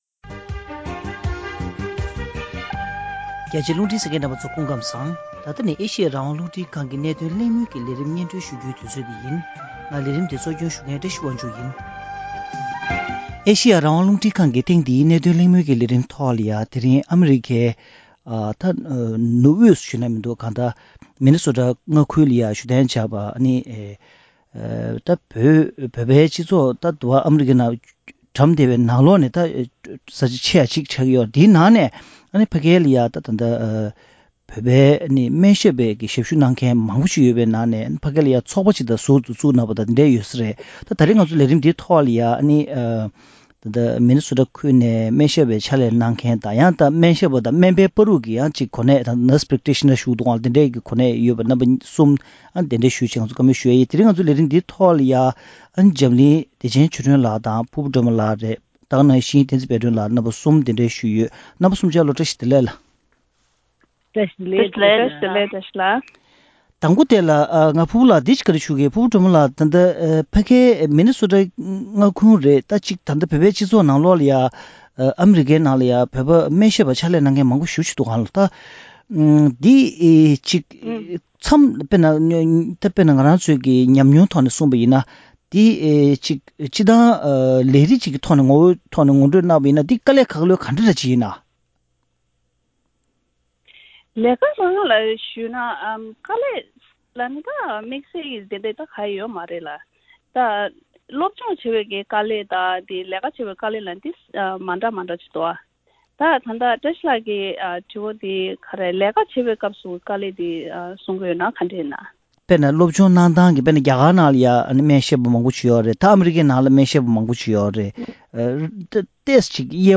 བོད་པའི་སྨན་ཞབས་ཚོགས་པ་དང་སྨན་ཞབས་པའི་ཆེད་ལས་སྐོར་མི་ནི་སོ་ཊའི་ཁུལ་དུ་གནས་བཞུགས་སྨན་ཞབས་པ་ཁག་དང་ལྷན་དུ་གླེང་མོལ་ཞུས་པ།